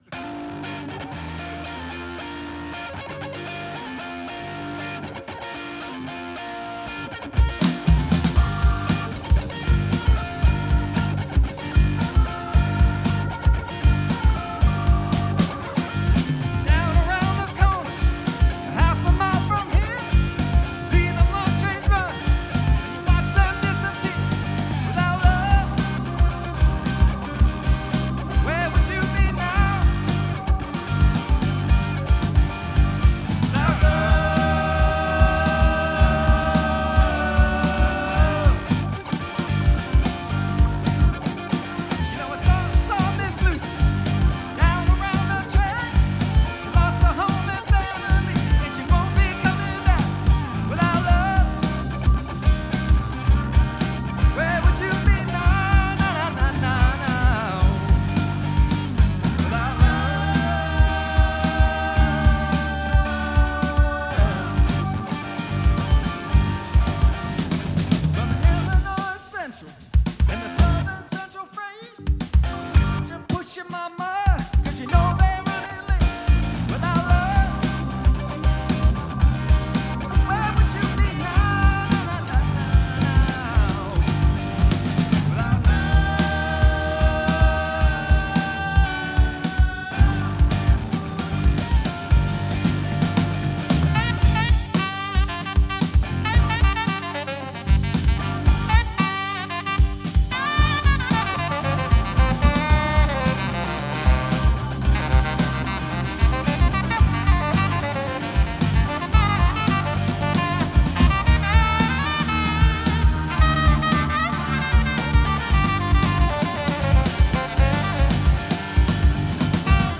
Gm